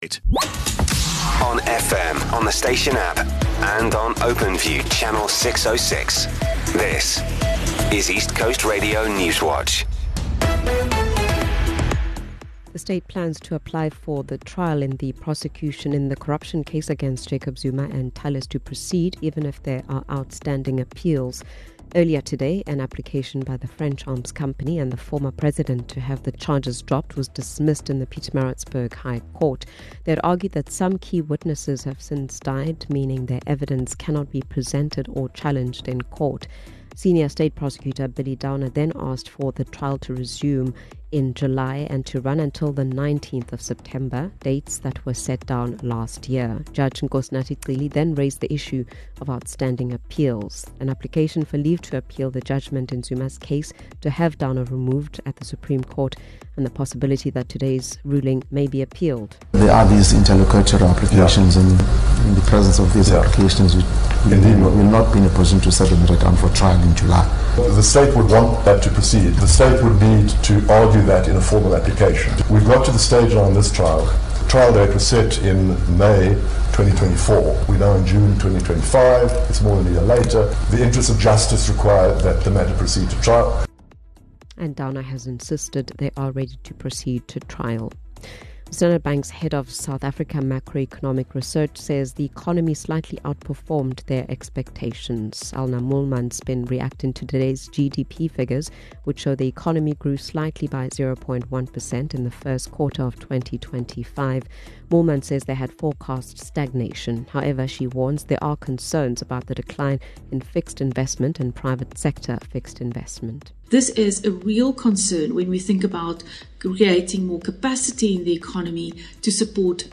We are KwaZulu-Natal’s trusted news source with a focus on local, breaking news. Our bulletins run from 6am until 6pm, Monday to Friday.